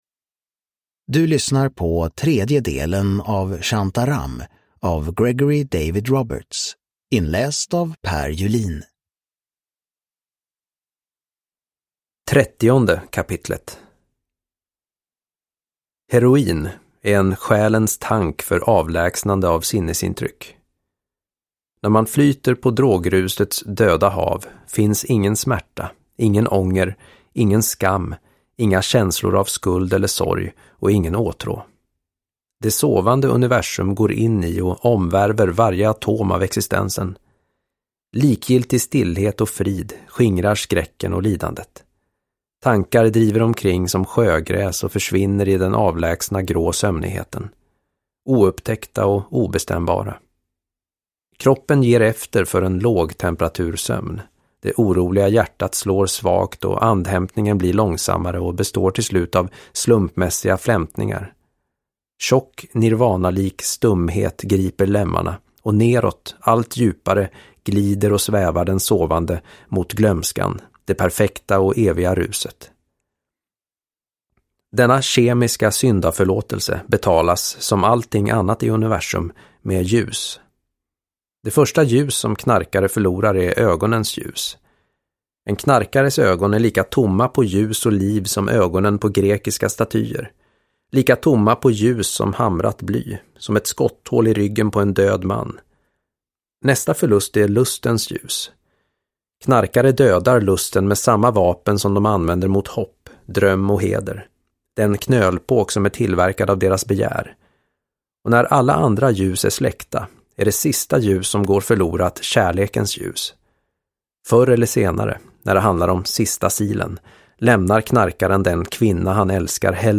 Shantaram. Del 3 – Ljudbok – Laddas ner